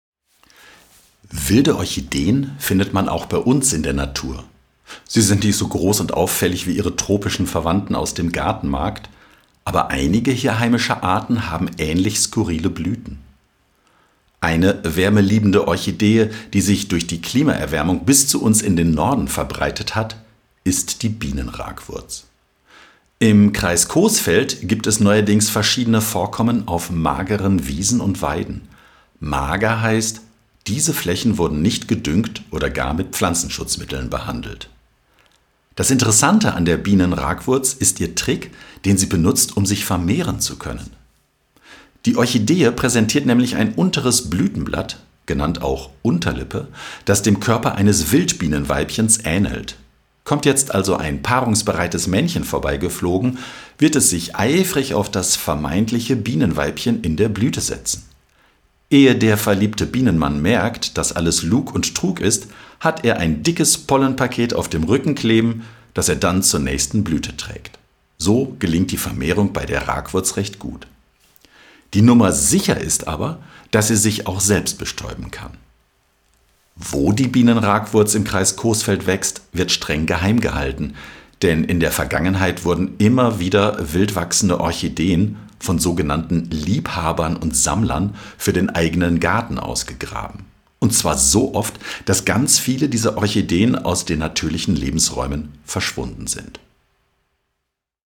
Hörspiel: Bienen-Ragwurz - eine Insektentäuschblume auf Ausbreitungskurs (MP3, 2 MB)